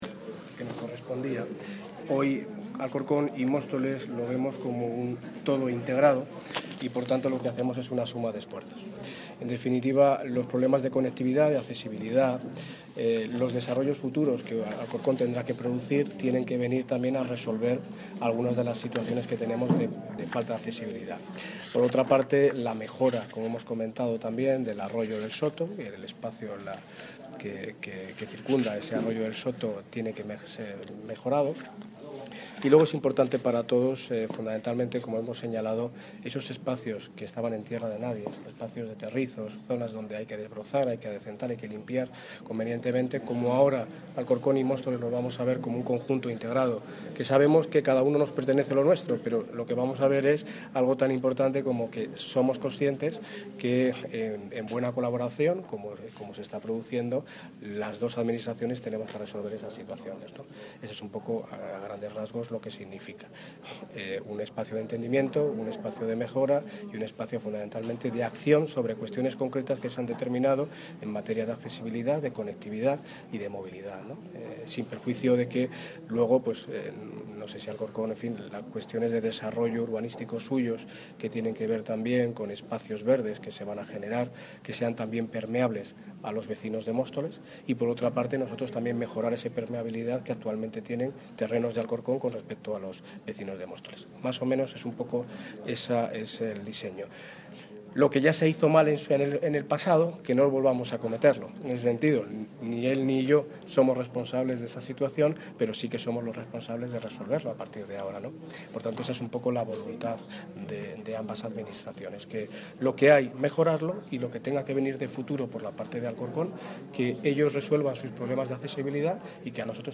Audio - Daniel Ortiz (Alcalde de Móstoles) Sobre Firma Acuerdo